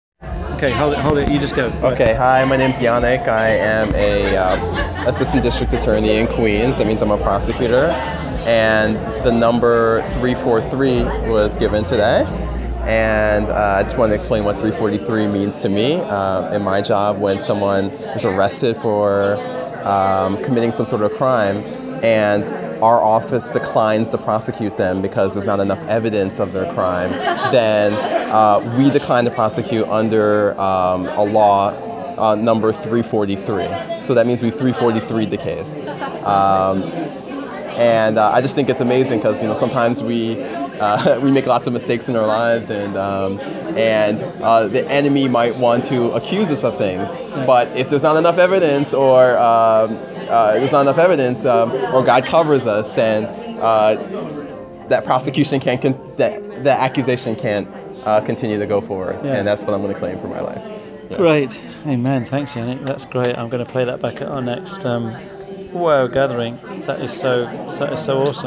Please click and listen to the testimony from the American prosecution lawyer (60 secs), how glad I was that I gave only the numbers 343!!!!!.